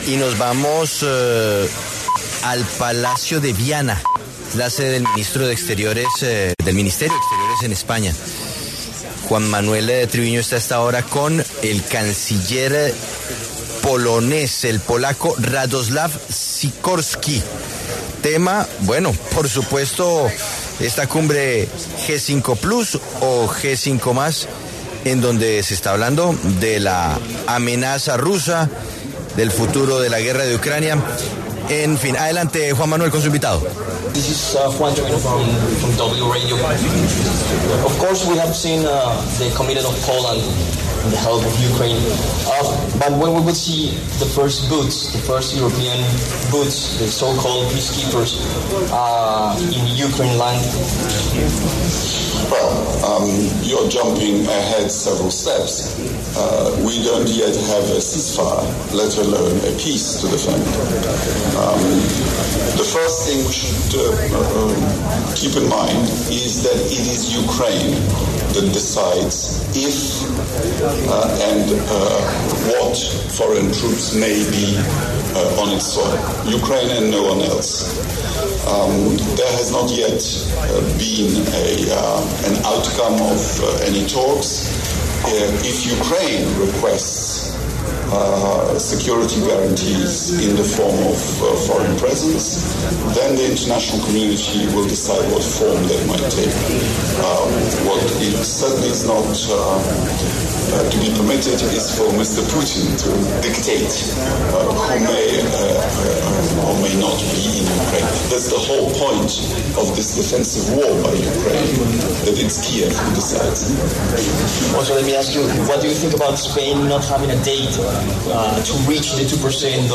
Por esta razón, Radoslaw Sikorski, canciller de Polonia que se encontraba en esa reunión de cancilleres, habló en La W sobre el tema.